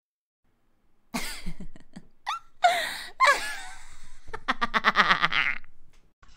جلوه های صوتی
دانلود صدای خنده زنانه از ساعد نیوز با لینک مستقیم و کیفیت بالا